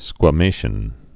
(skwə-māshən)